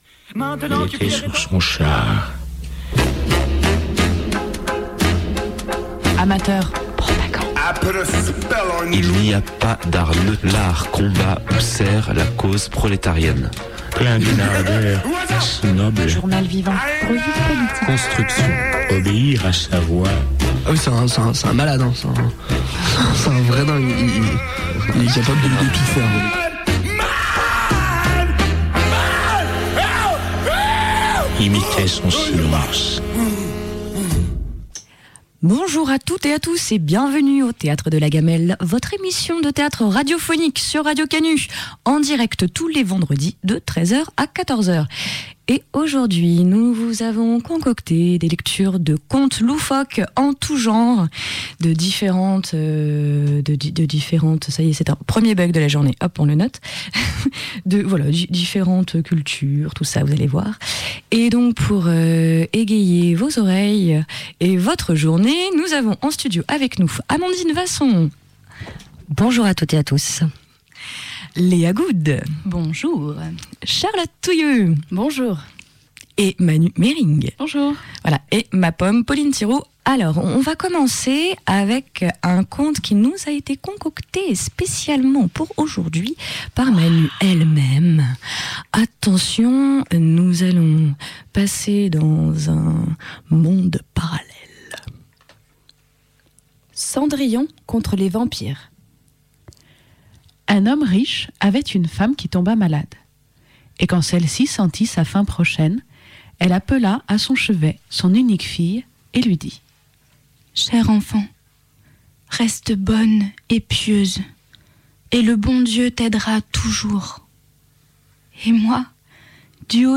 Lectrices